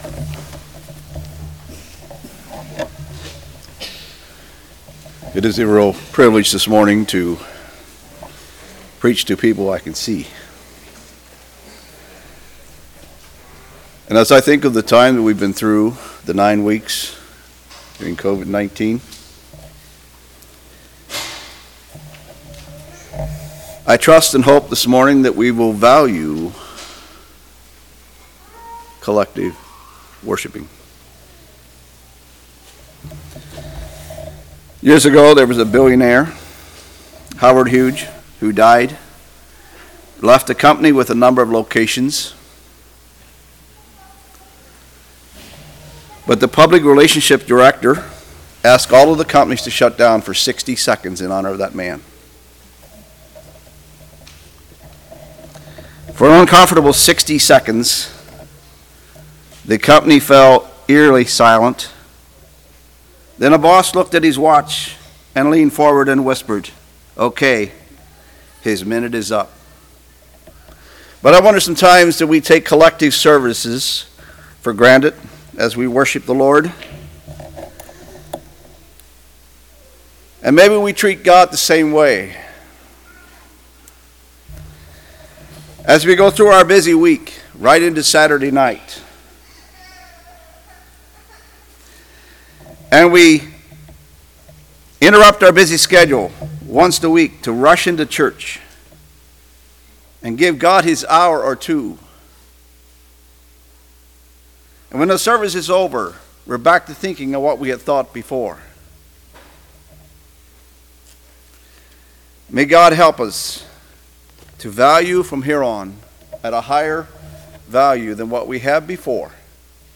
2020 Sermon ID